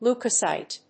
音節leu・ko・cyte 発音記号・読み方
/lúːkəsὰɪt(米国英語), ˈlu:kʌˌsaɪt(英国英語)/